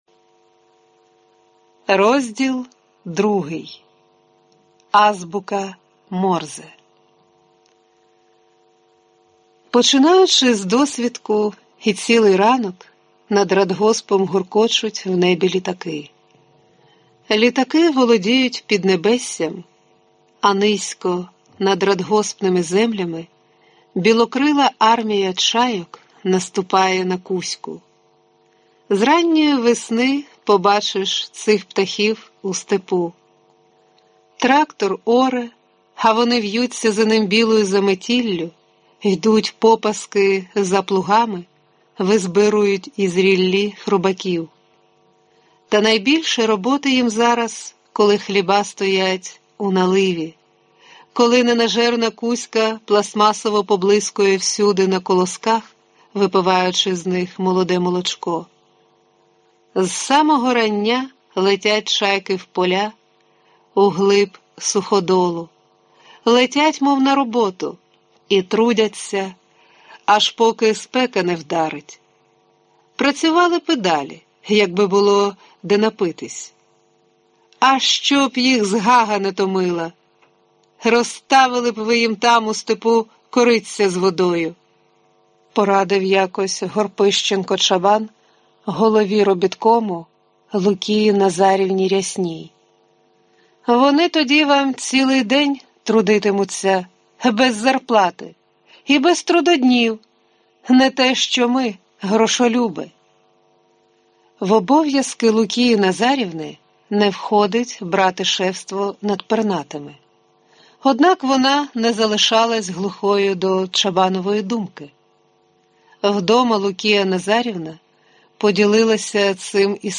Аудиокнига Азбука Морзе. Новела | Библиотека аудиокниг